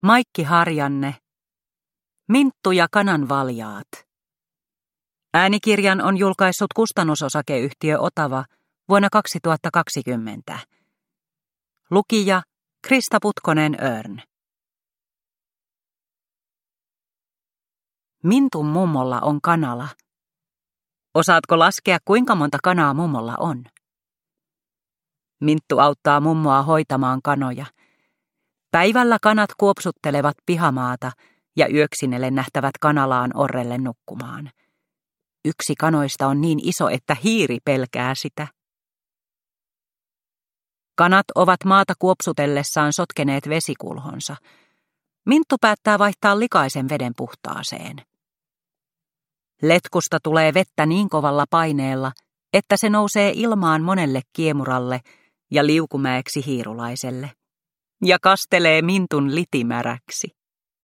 Minttu ja kananvaljaat – Ljudbok – Laddas ner